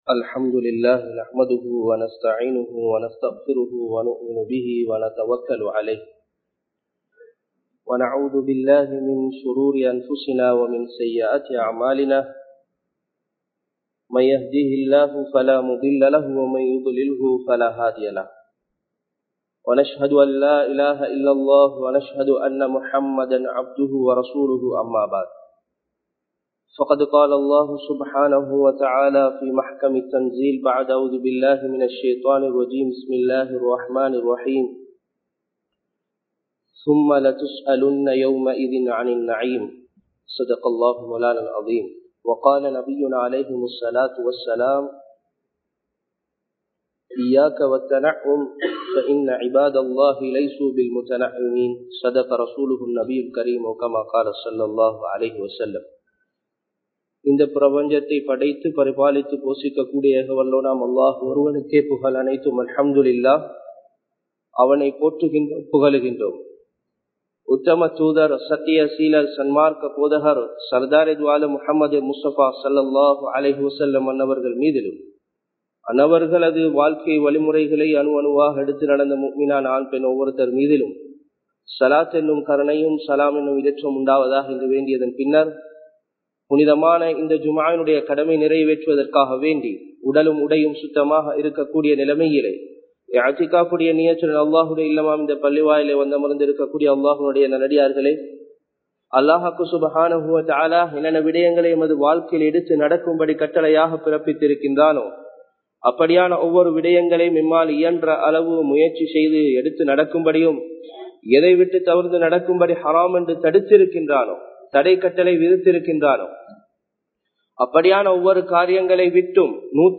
ஏமாற்றும் இவ்வுலகம் (The deceptive world) | Audio Bayans | All Ceylon Muslim Youth Community | Addalaichenai
Gelioya jumua Masjidh